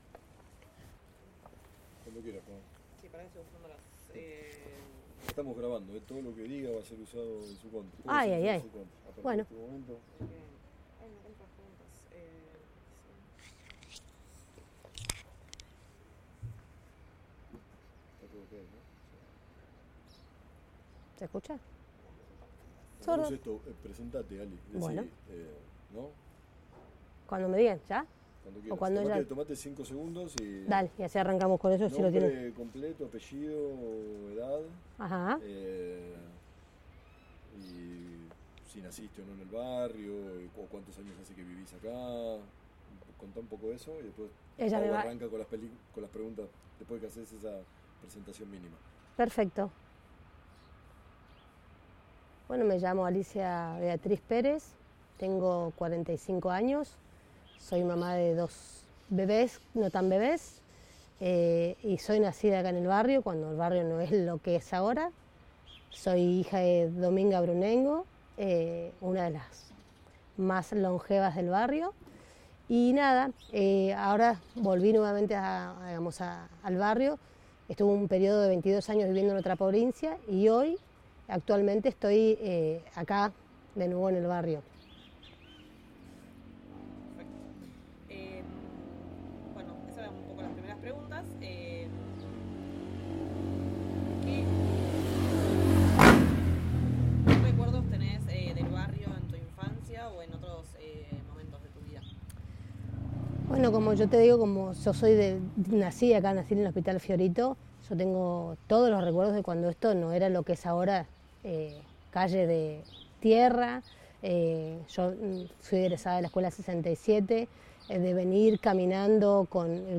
1 grabación sonora en soporte magnético
Entrevista oral